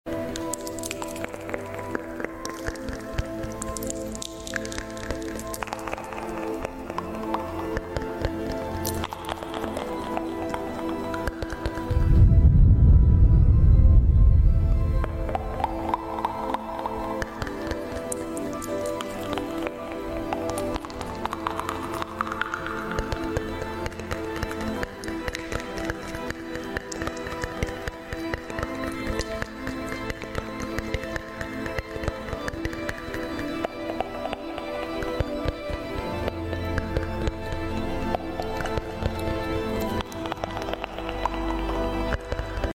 The most beautiful thing I’ve seen for asmr!